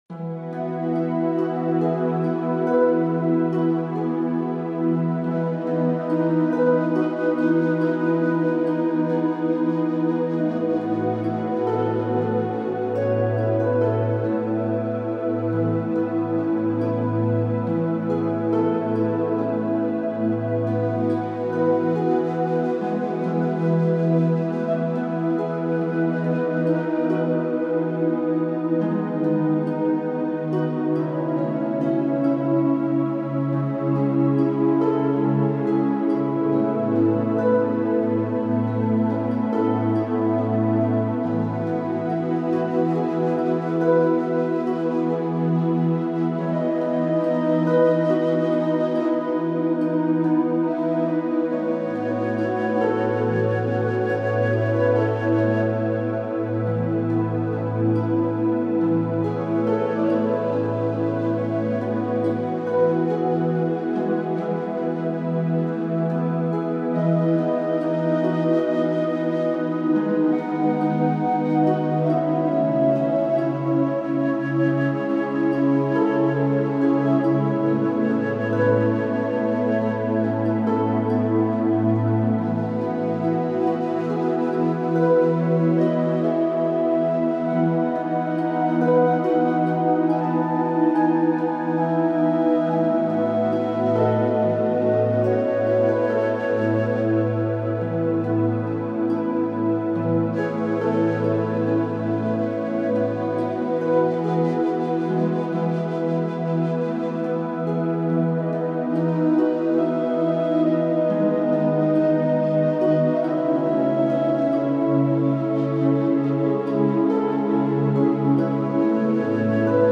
即座に眠るための森の雷と雷雨